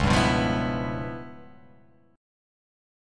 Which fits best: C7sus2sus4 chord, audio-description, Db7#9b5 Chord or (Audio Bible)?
Db7#9b5 Chord